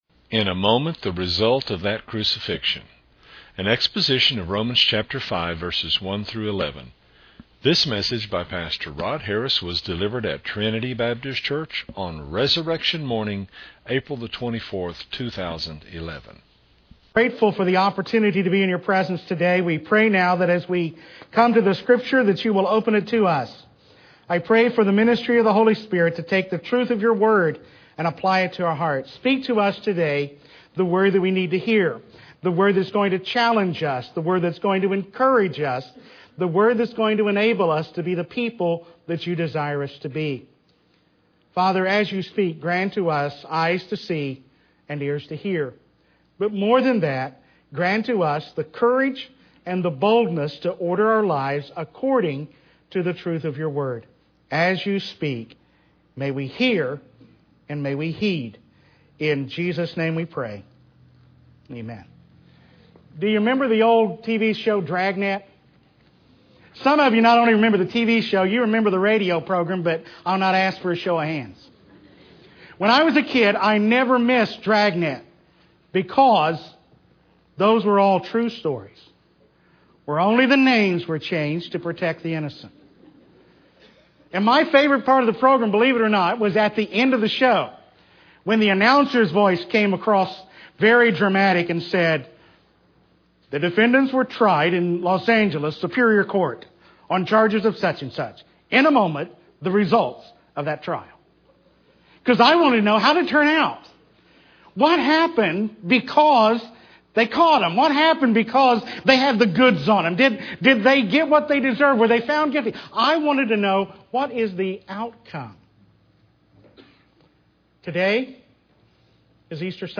was delivered at Trinity Baptist Church on Resurrection Morning, April 24, 2011.